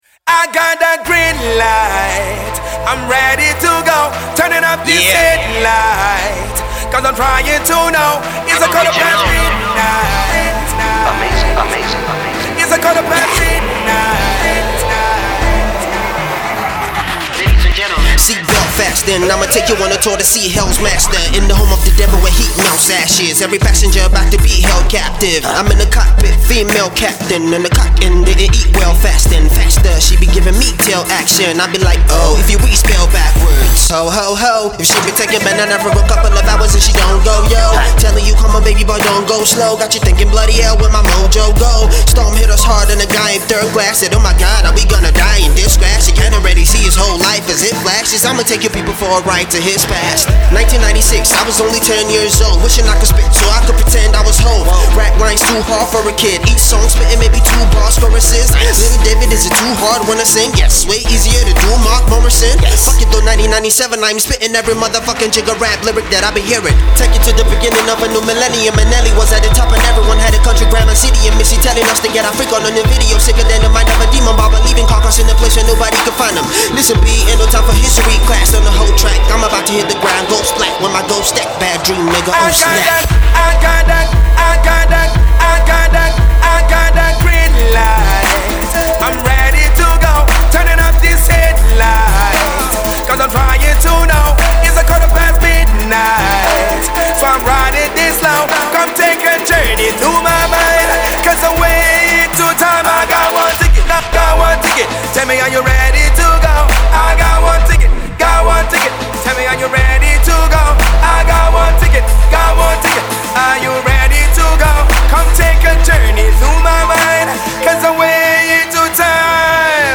Hip-Hop cut